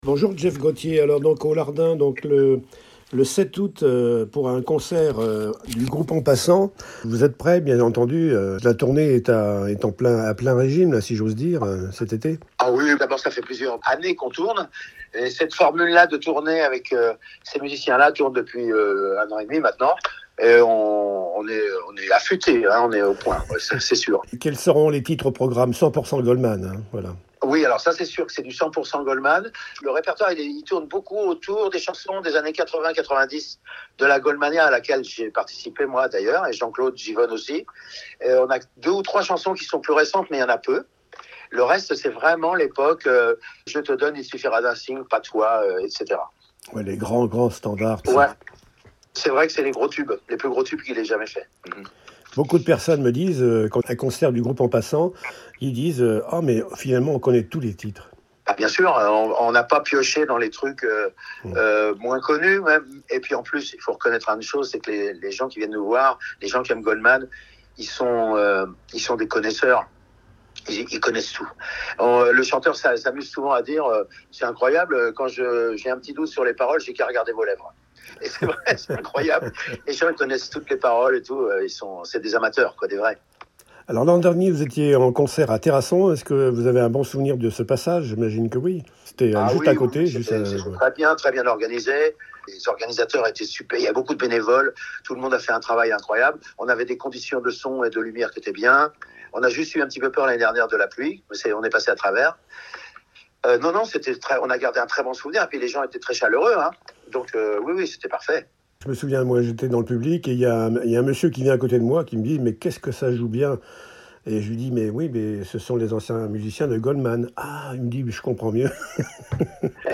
—>>> Interview audio des organisateurs de cette semaine de fête au Lardin ;